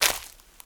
STEPS Leaves, Walk 19.wav